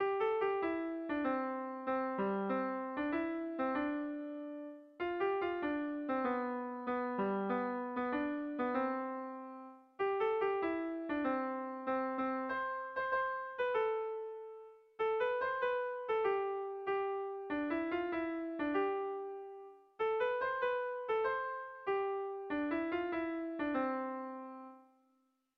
Zortziko txikia (hg) / Lau puntuko txikia (ip)
A1BA2D1D2